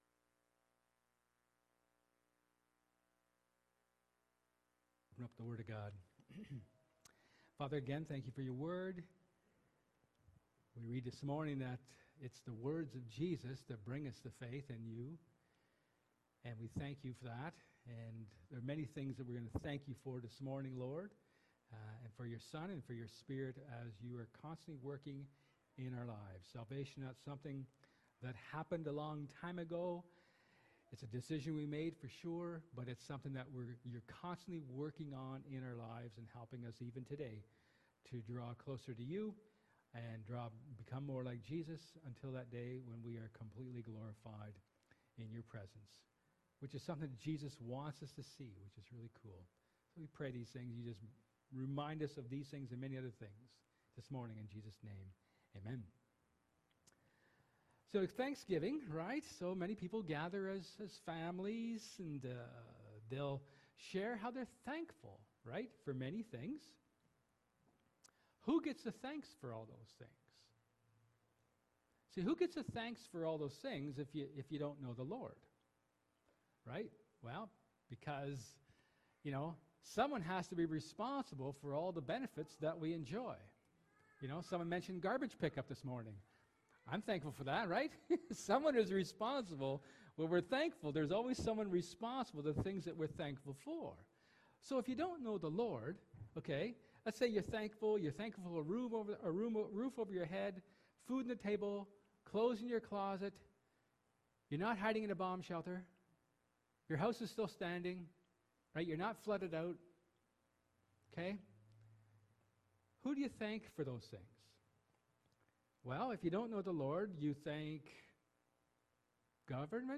1 Corinthians 15:50-58 Service Type: Sermon